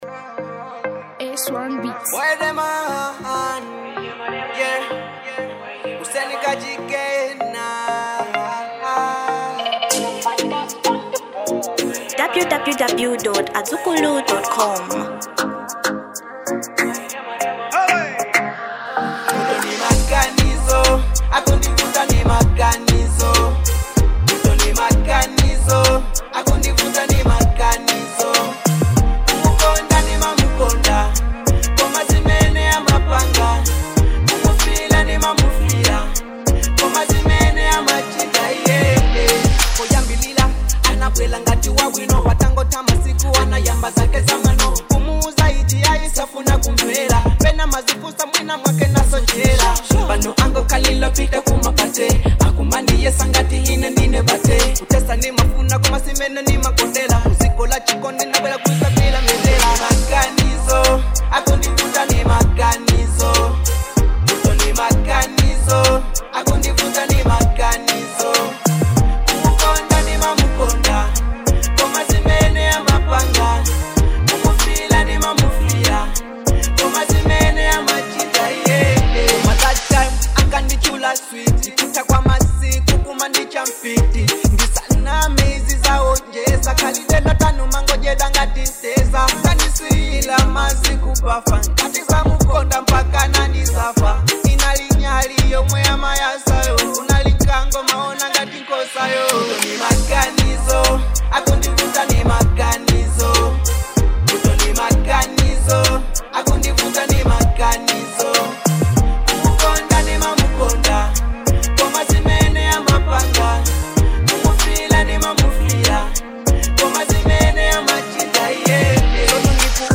Genre Afro Pop